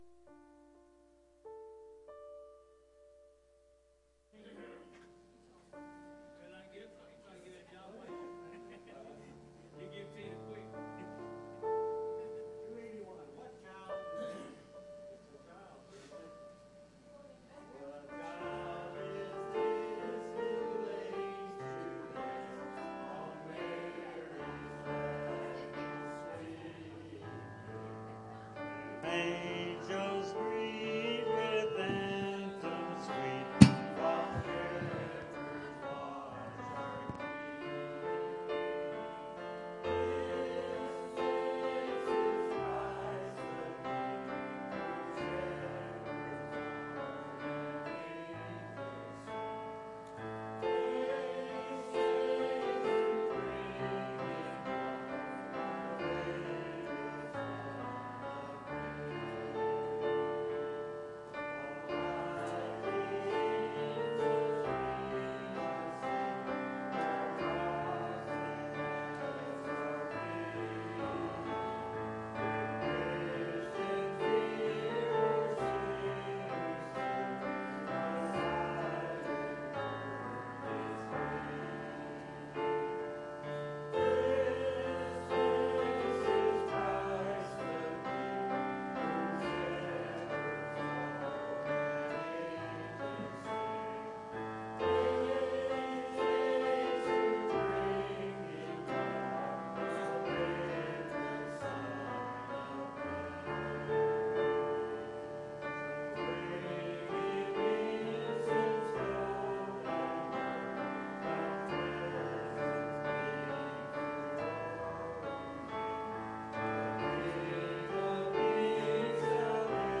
Bible Text: Isaiah 7:14; 9:6; 40:3; 35:4-6; 53:12; 53:3; Zechariah 9:9; 11:12; 12:10; Psalms 22:14-17; Luke 2:11; Matthew 1:19-25 | Preacher